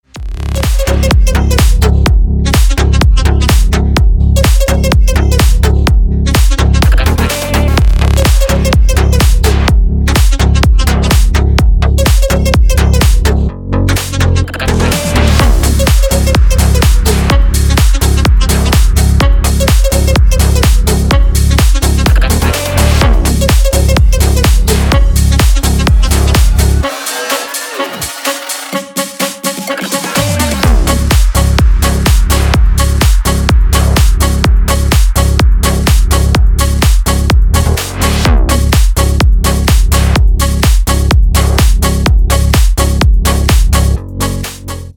• Качество: 320, Stereo
Electronic
EDM
без слов
Стиль: future house